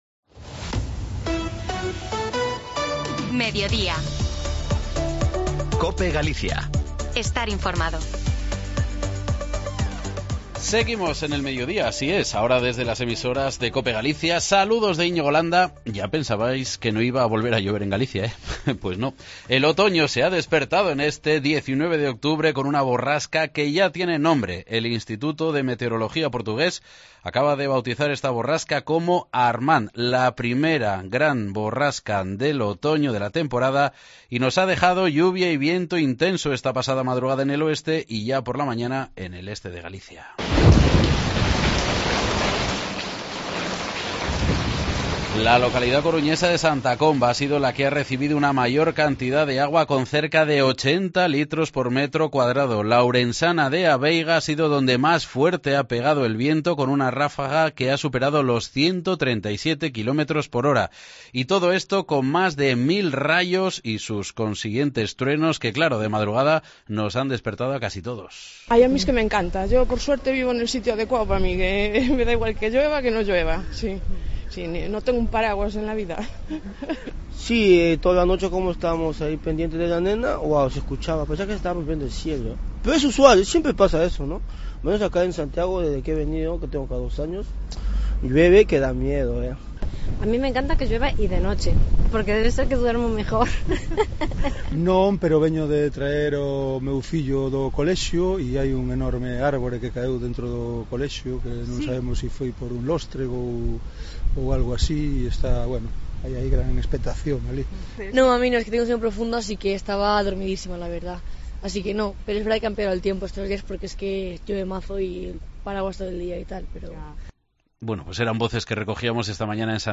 escuchamos la opinión de la calle tras el paso de primer gran temporal del otoño provocado por la Borrasca Armand